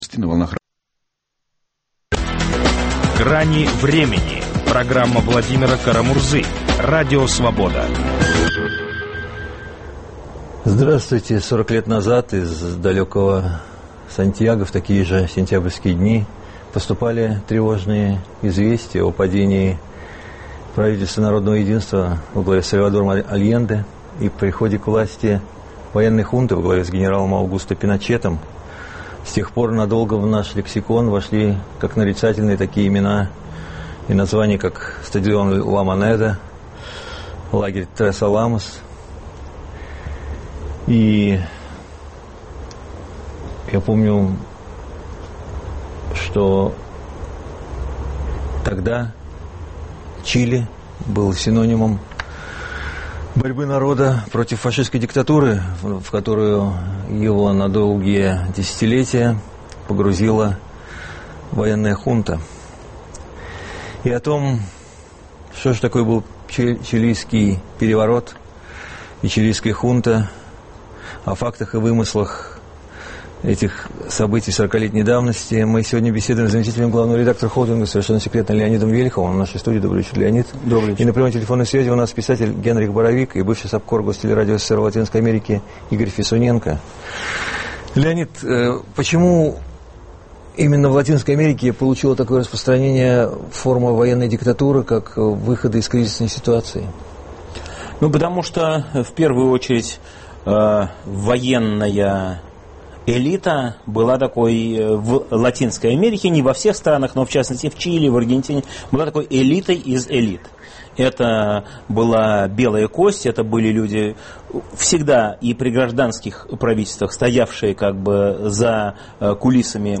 Чилийская хунта: факты и вымысел. 40 лет назад путчисты свергли Сальвадора Альенде. Пиночет - один из немногих диктаторов, представших при жизни перед судом истории. Об этом беседуем